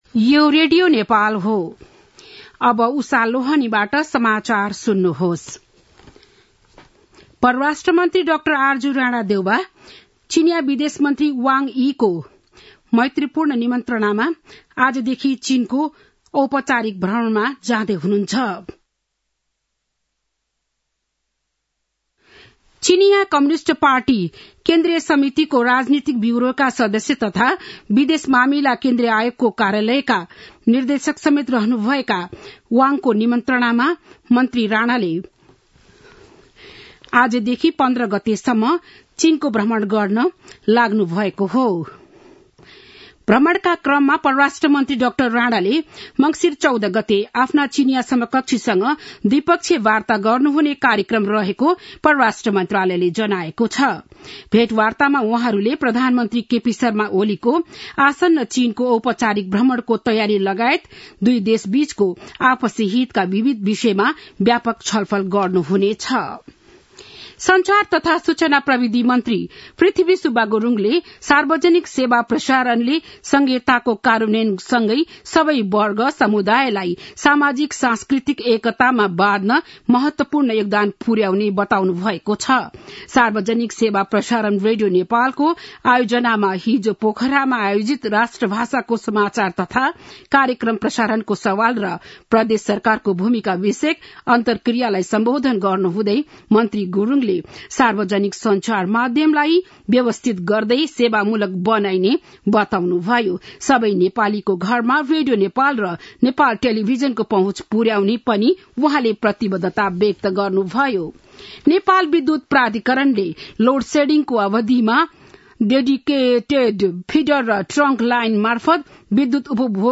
बिहान ११ बजेको नेपाली समाचार : १४ मंसिर , २०८१
11-am-nepali-news-1-11.mp3